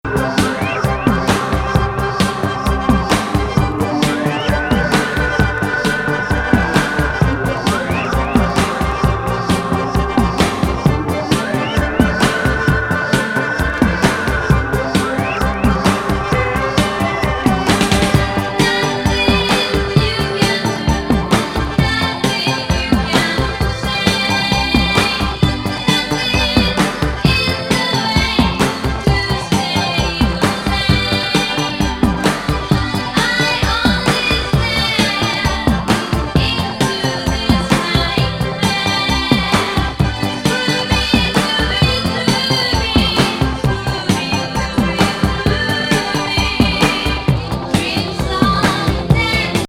エフェクタブル&コミ上げ